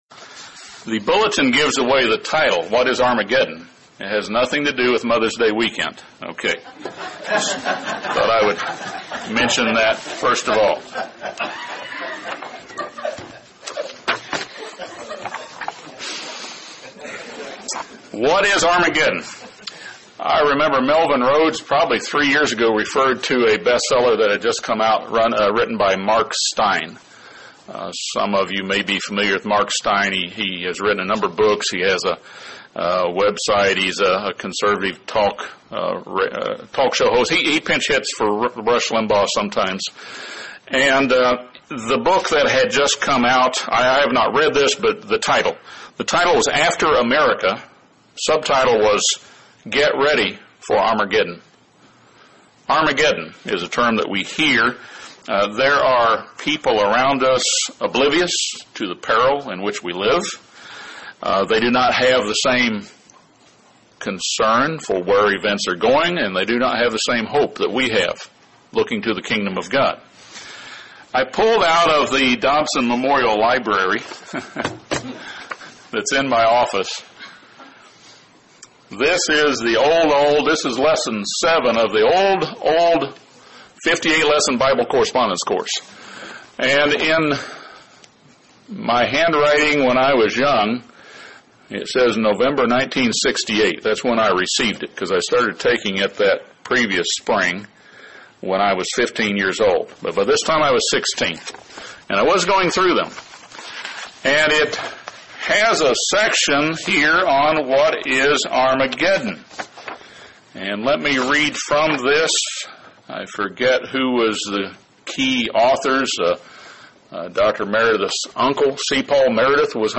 It refers to a gathering place for armies, but not to a battle. This sermon discusses what armageddon is not, what it is, and then offers an overview of the end-time events leading up to the return of Jesus Christ as King of kings.